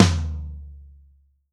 TOM 3H    -L.wav